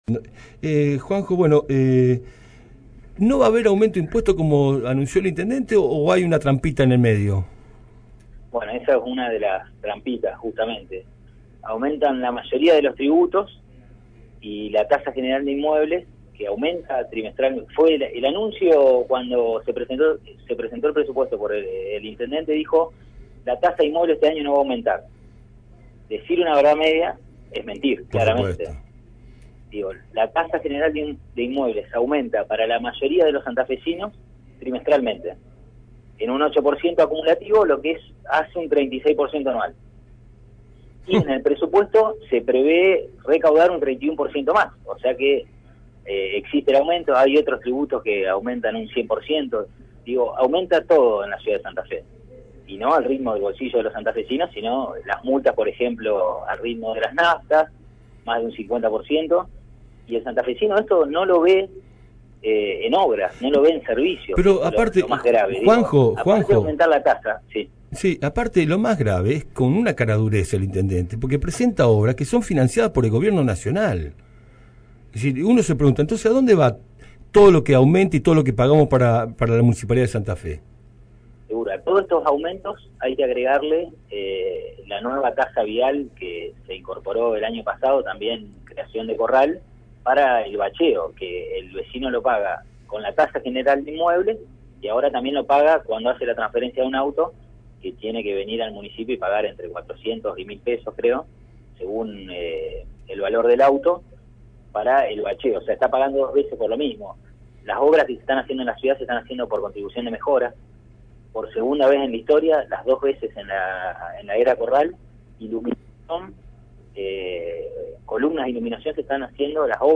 Durante la Sesión de este jueves, los Concejales decidieron aprobar el Mensaje Nº43/14 enviado desde el Ejecutivo que tiene que ver con el Presupuesto 2015. Sin embargo, el Bloque Justicialista no acompañó la medida. Escuchá al concejal Juan José Saleme.